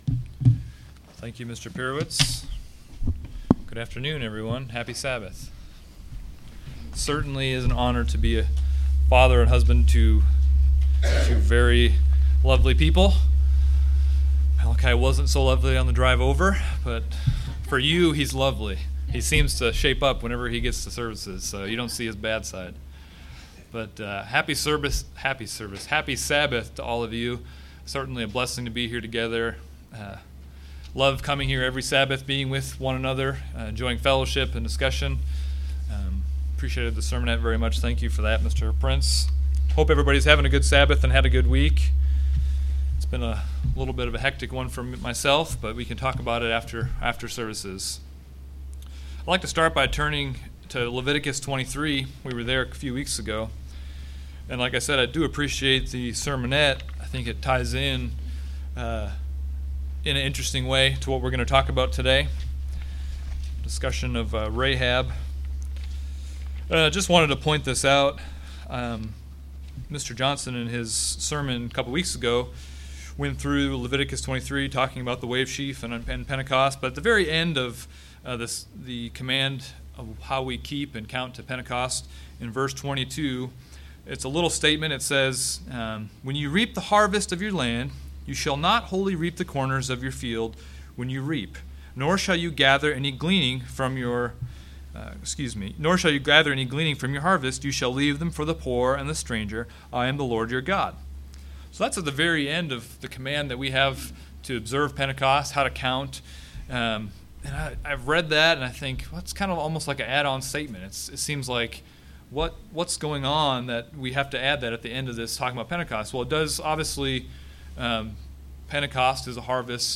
In this sermon, the speaker goes through a verse by verse reading of the first two chapters of the book of Ruth and expounds on lessons we can learn.